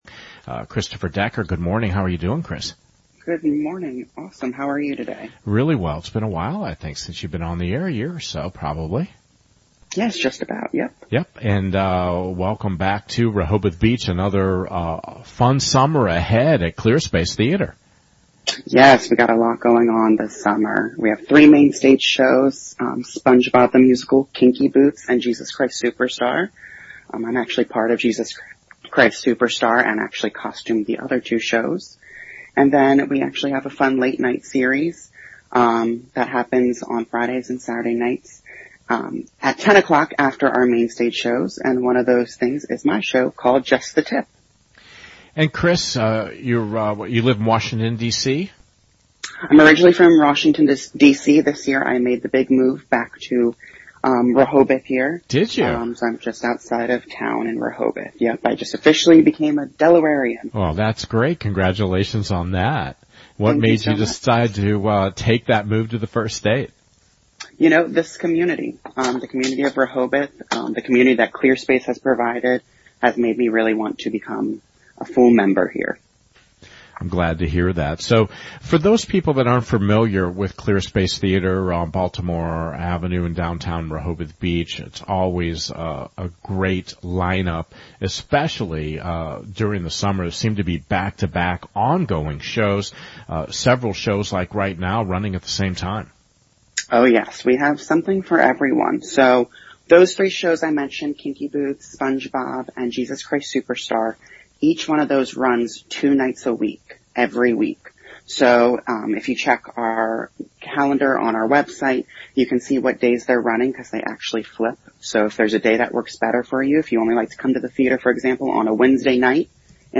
Click on the image or link for the audio interview.